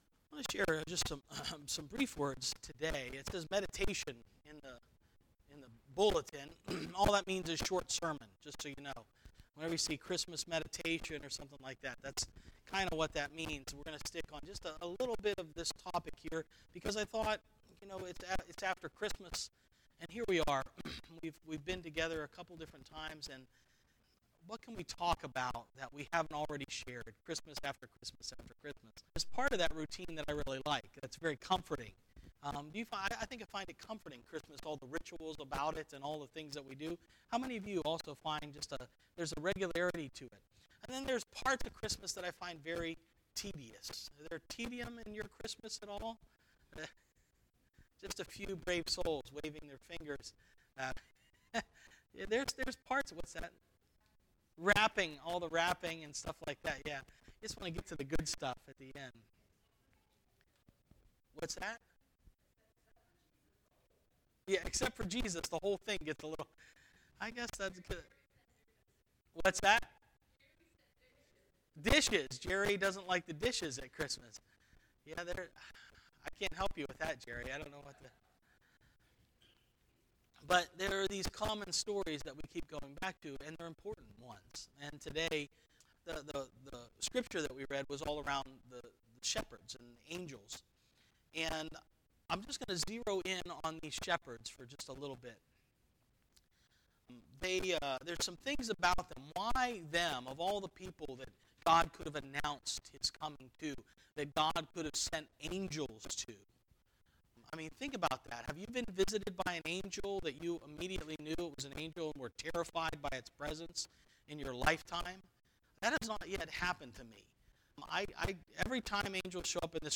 12-30-18 Sermon